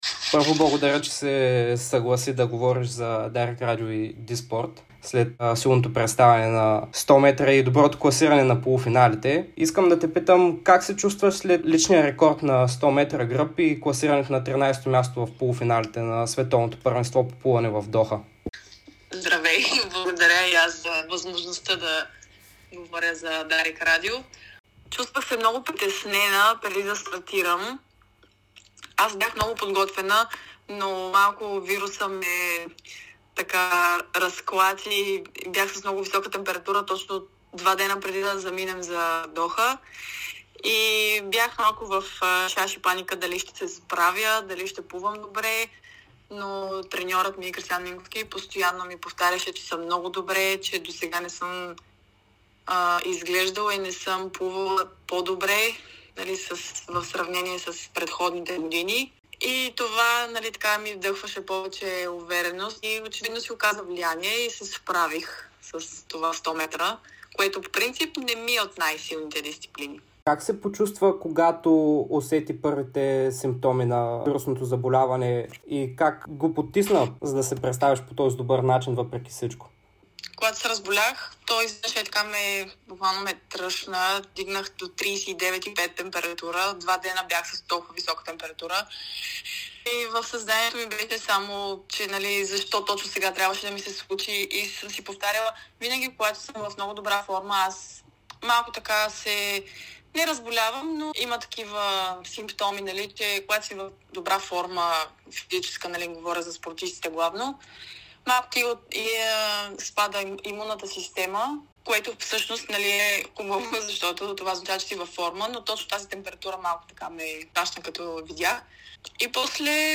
Българската плувкиня Габриела Георгиева говори специално пред Dsport и Дарик радио, след като завърши на 13-о място в полуфиналите на 100 метра гръб на Световното първенство по плуване в Доха, Катар.